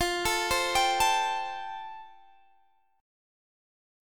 Listen to Am7/F strummed